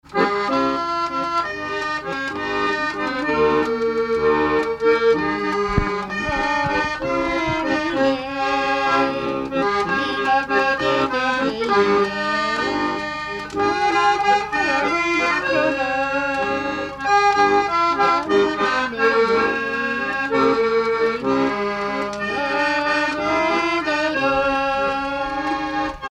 Localisation Brizon
Catégorie Pièce musicale inédite